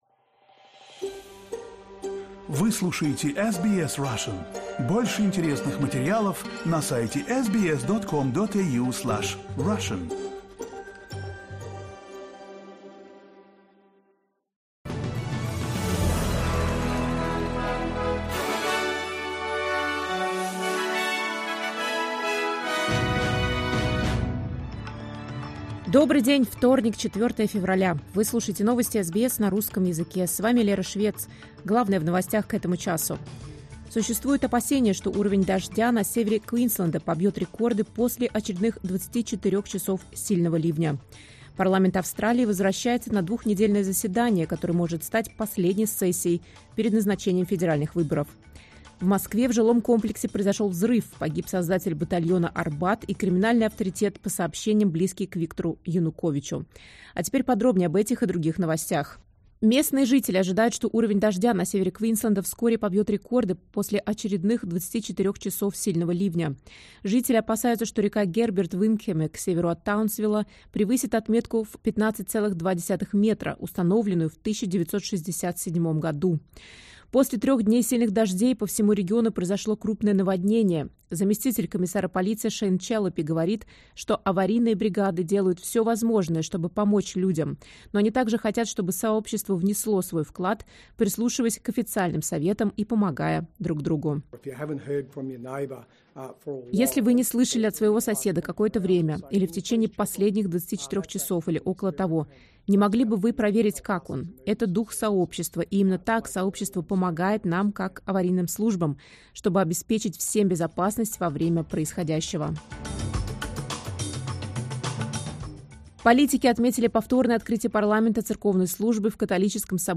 Новости SBS на русском языке — 04.02.2025